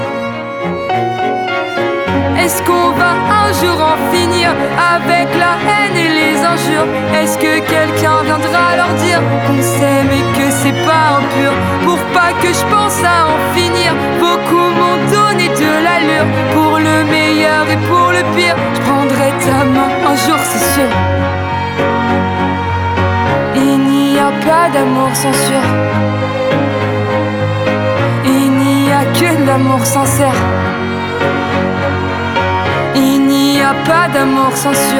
Жанр: Поп
# French Pop